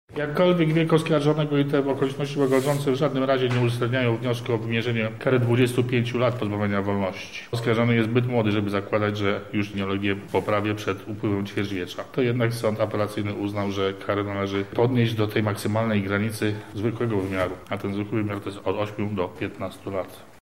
Sąd uznał, że zasadne są apelacje dotyczące zbyt łagodnego potraktowania oskarżonego. Nie zdecydował się jednak na wymierzenie kary 25 lat lub dożywotniego pozbawienia wolności, – mówi Sędzia Sądu Apelacyjnego Wojciech Zaręba.